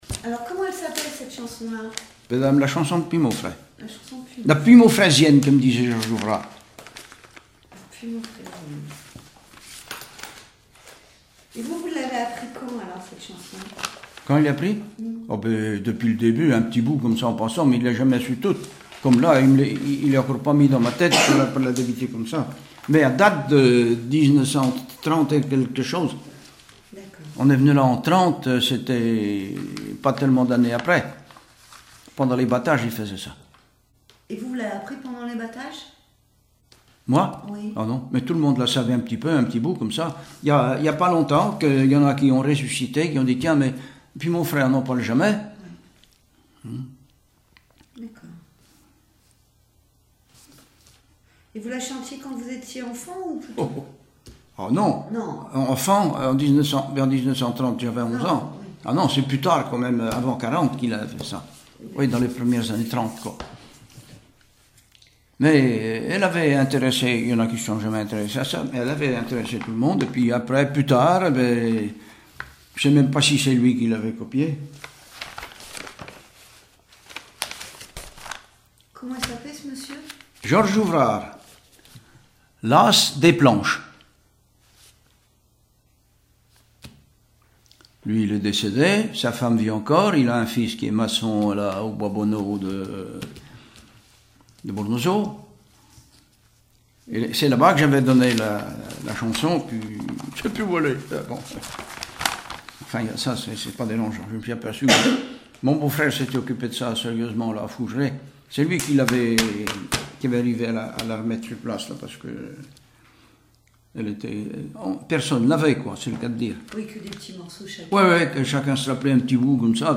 Enquête Arexcpo en Vendée-C.C. Deux Lays
Catégorie Témoignage